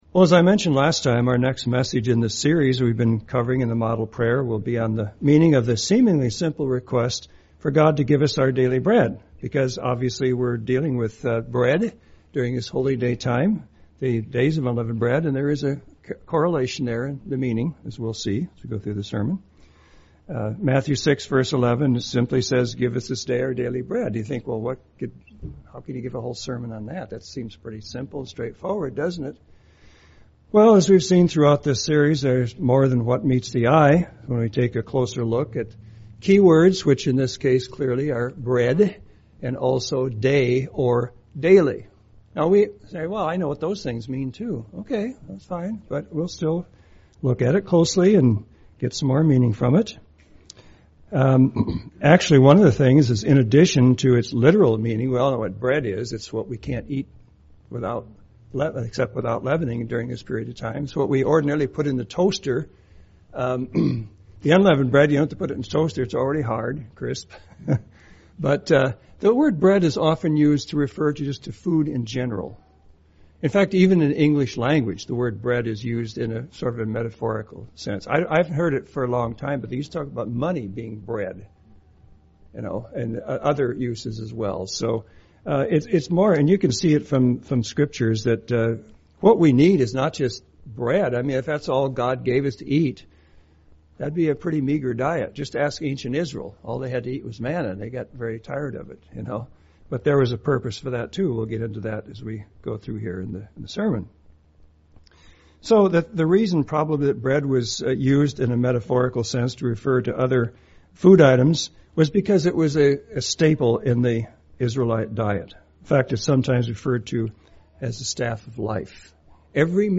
UCG Sermon bread model prayer Studying the bible?